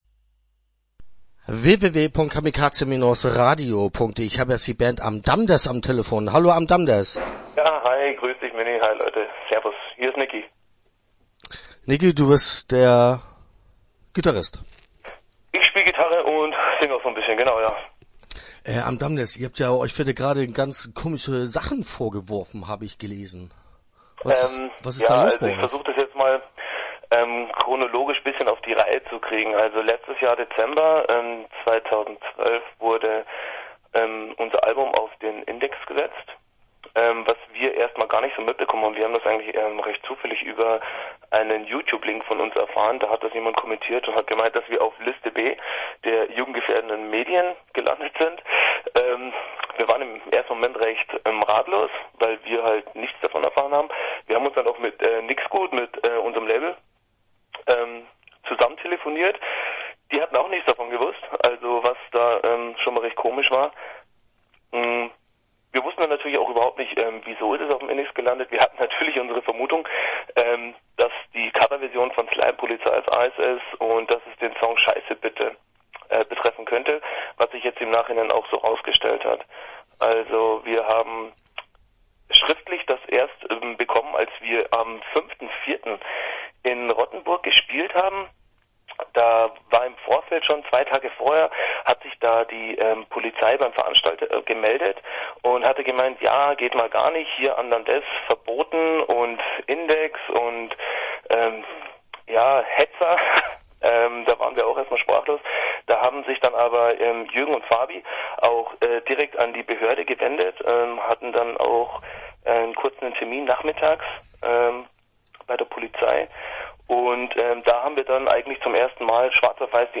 Interview Teil 1 (16:35)